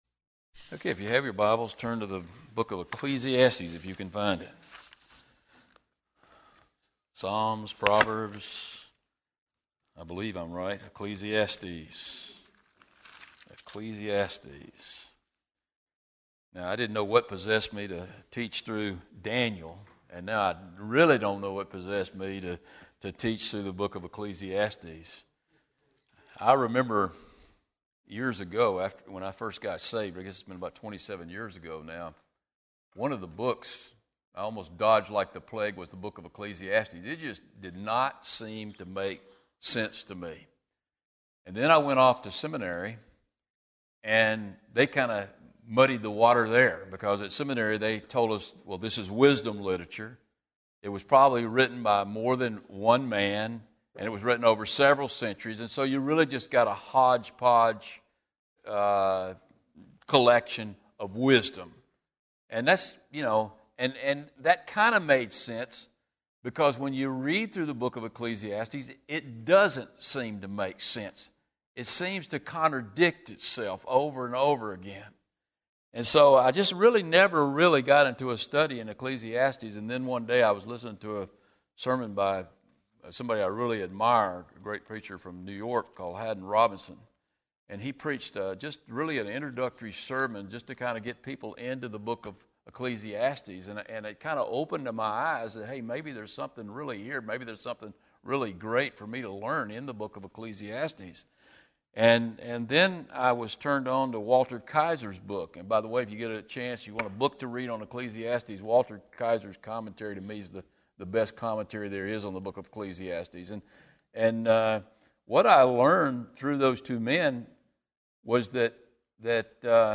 These teachings on Ecclesiastes are from Wednesday evening service.